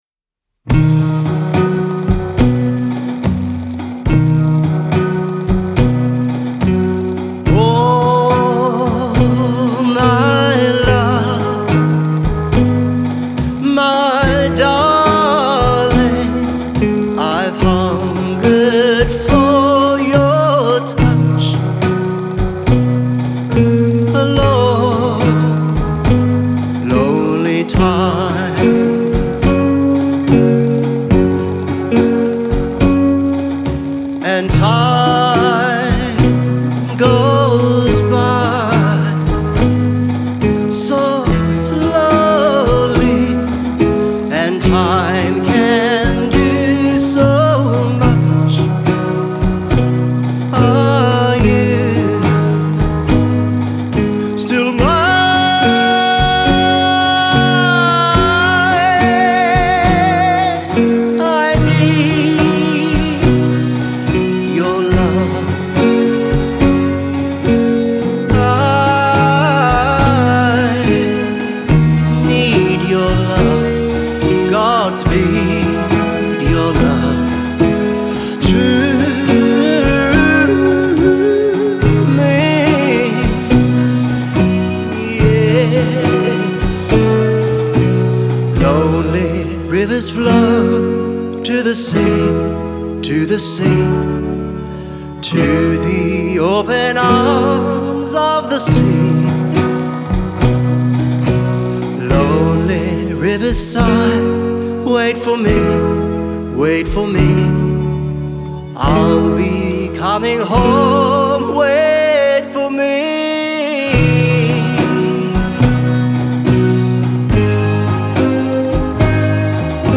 shows off an impressive vocal range and sheer emotion